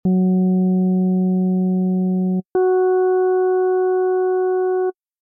Again, turn up the volume so that you can hear this new layer to the tone but not so much that it stands out as a separate tone in it’s own right.